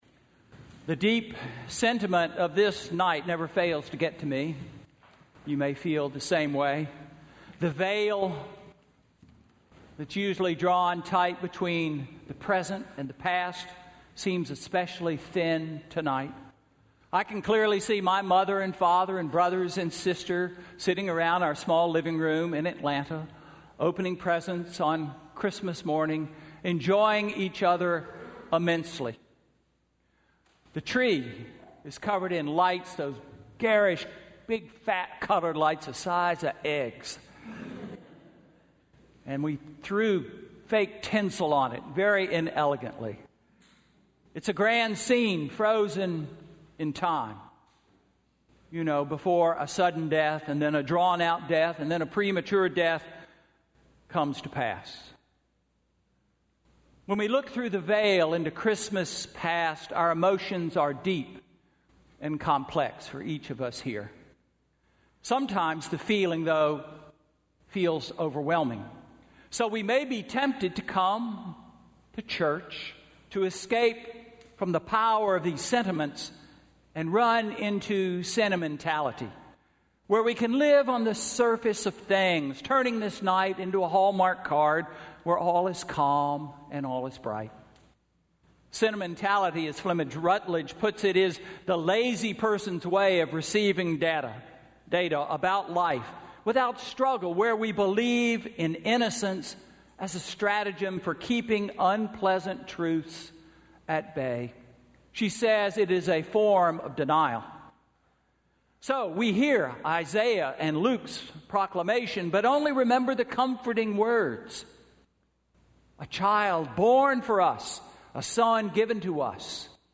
Sermon–Christmas Eve–2015